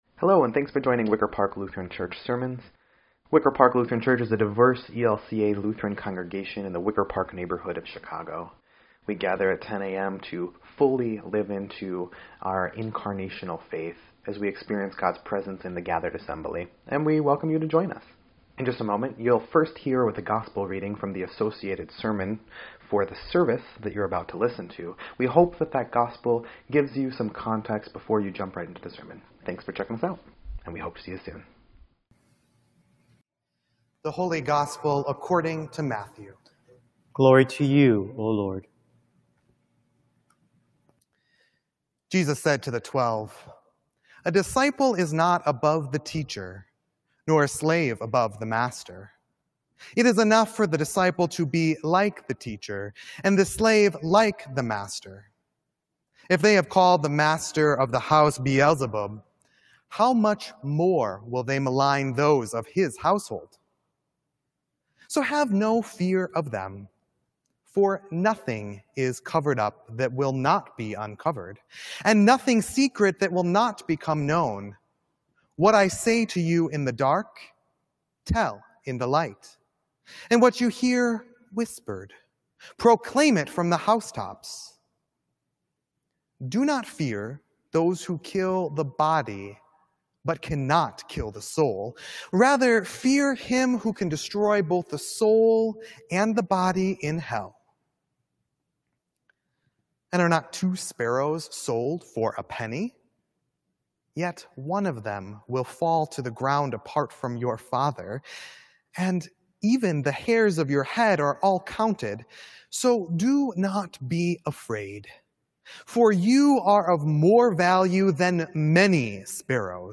6.21.20_Sermon_EDIT.mp3